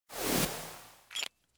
fill_dragonbreath2.ogg